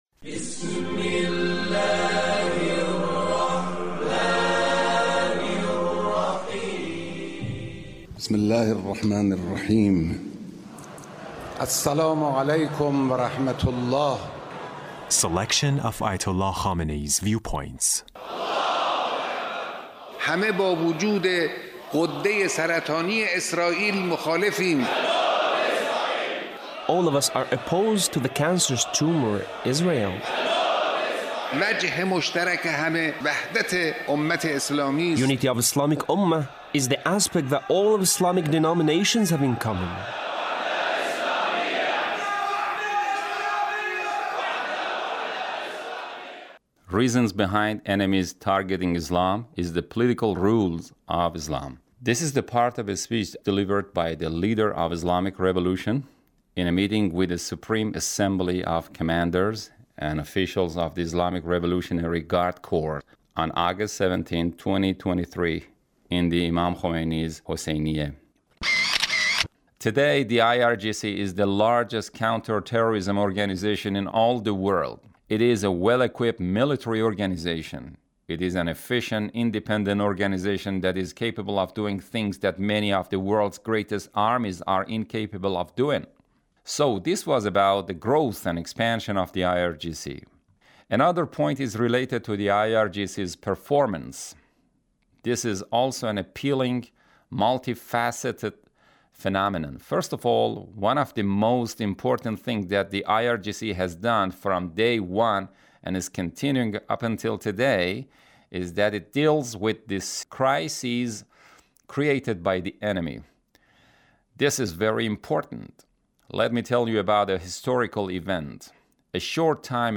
Leader's Speech (1808)
Leader's Speech in a meeting with Revolution in a meeting with the Supreme Assembly of Commanders and Officials of the Islamic Revolutionary Guard Corps (...